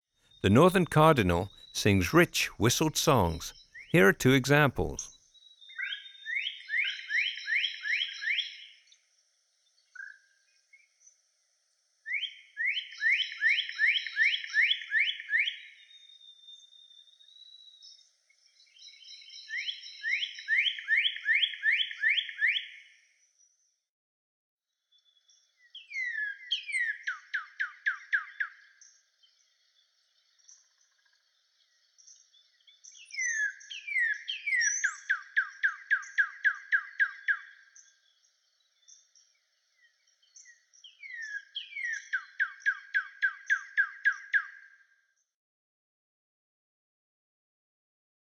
Cardinal
169-northern-cardinal.m4a